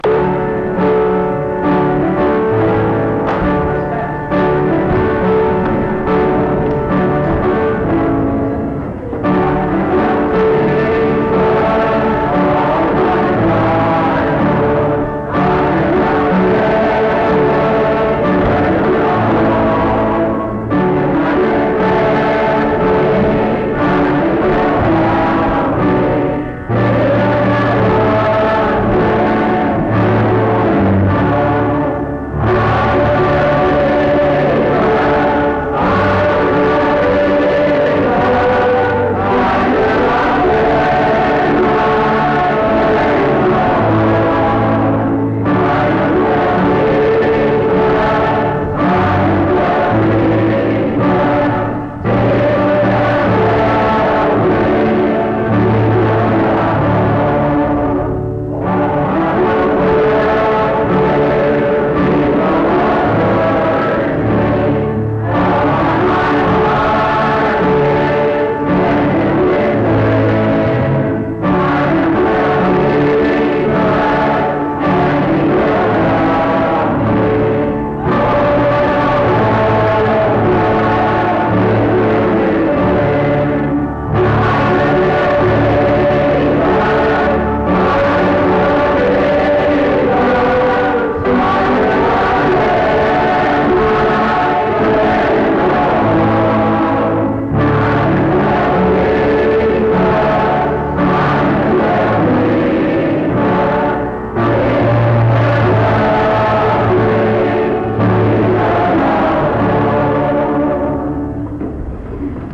Mount Union Methodist Church II, rural, Monongalia County, WV, track 145X.